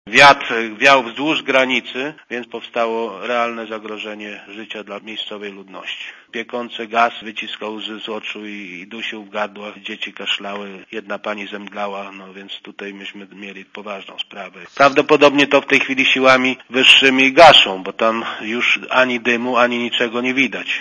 Posłuchaj komentarza starosty lubaczowskiego, Józefa Michalika
starosta.mp3